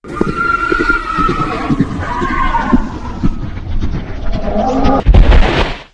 Boosted sound for headless horseman. 2025-02-11 19:02:51 -05:00 45 KiB (Stored with Git LFS) Raw History
headless_horseman_dies.ogg